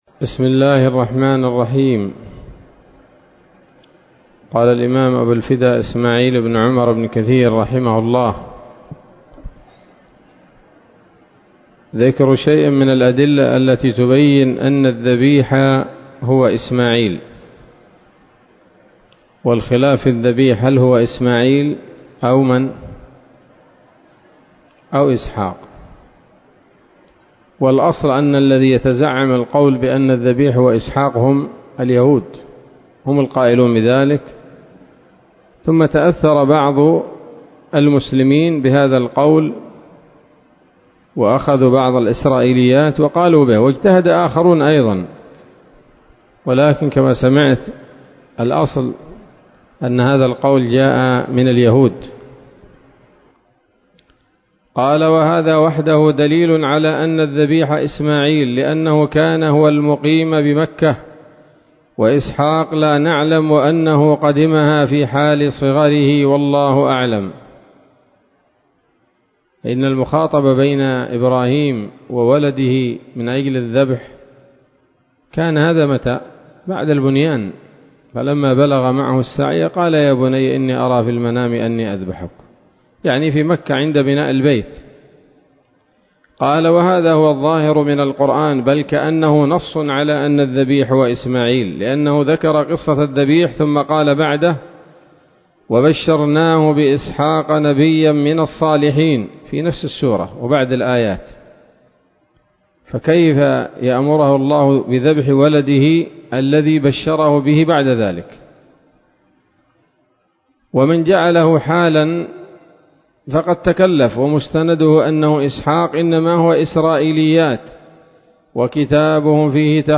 الدرس التاسع والأربعون من قصص الأنبياء لابن كثير رحمه الله تعالى